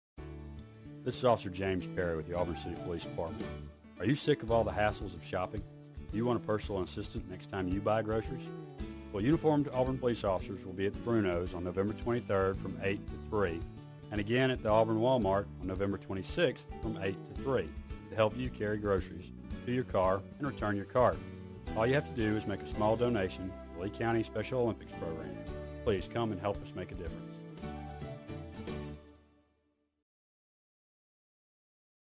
Radio News Release